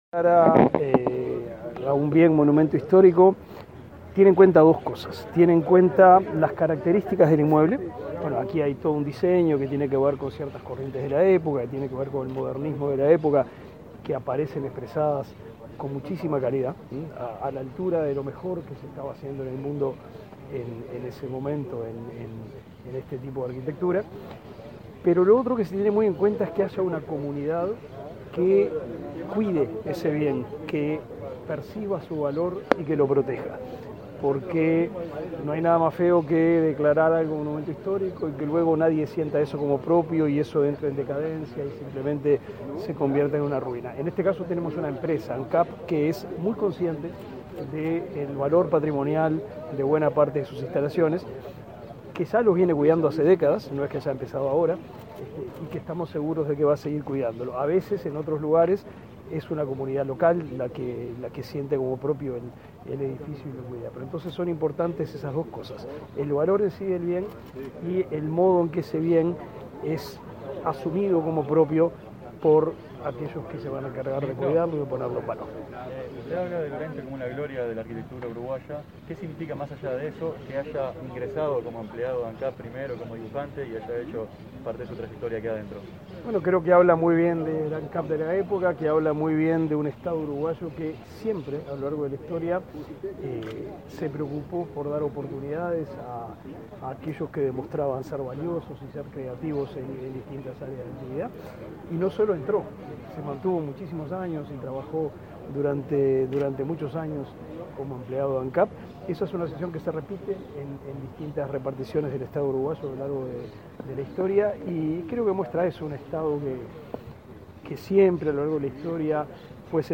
Declaraciones a la prensa del ministro de Educación y Cultura